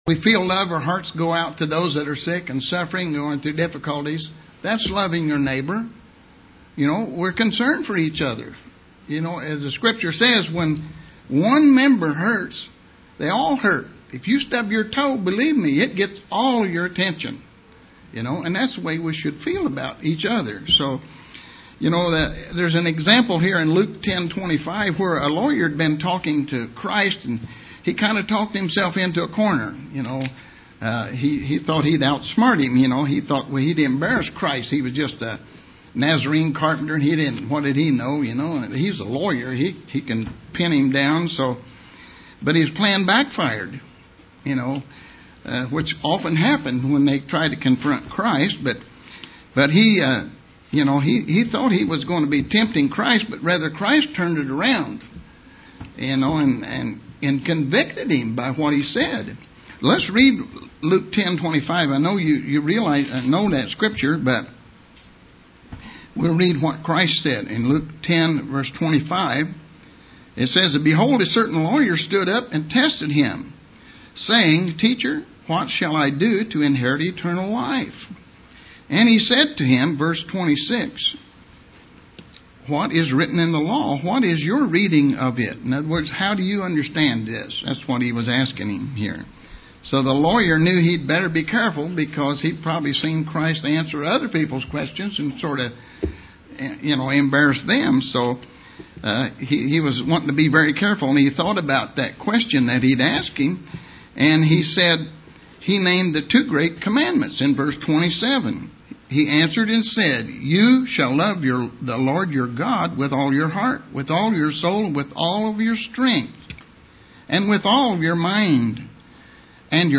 Given in Terre Haute, IN
UCG Sermon Studying the bible?